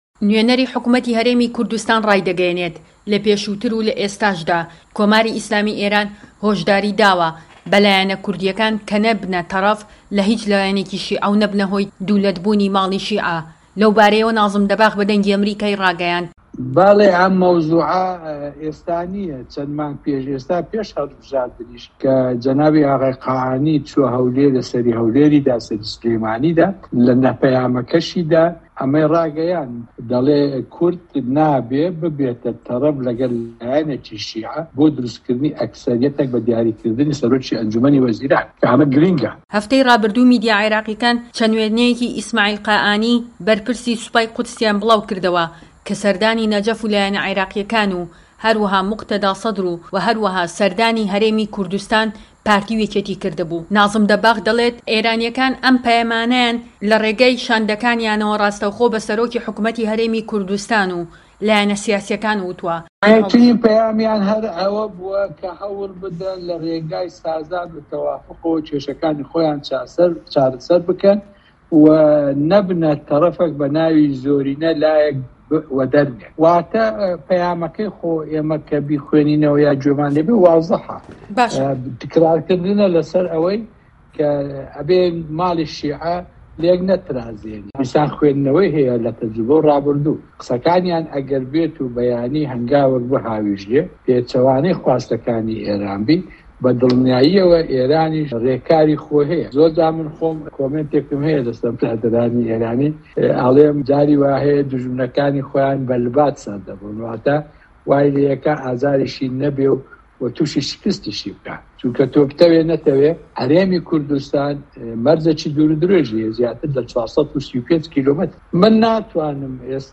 وتووێژ لەگەڵ لیوا عەبدولخالق تەڵعەت